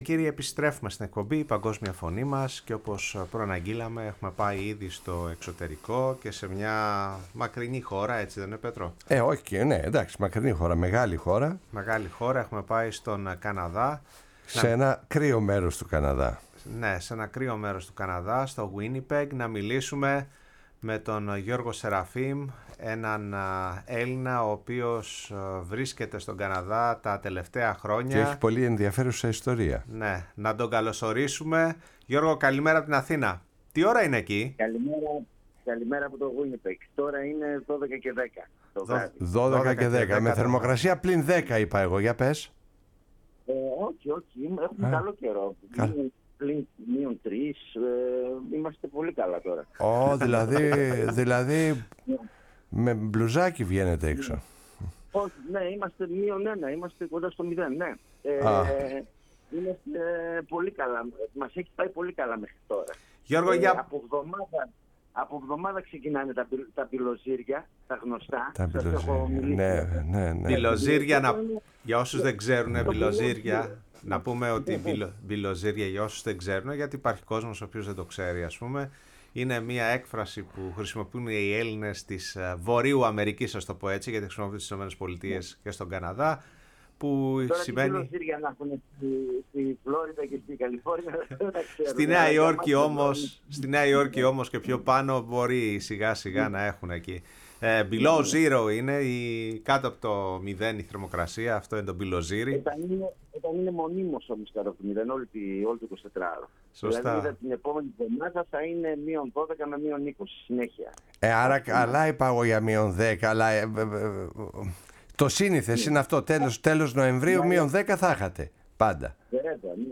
στην εκπομπή «Η Παγκόσμια Φωνή μας» στο ραδιόφωνο της Φωνής της Ελλάδος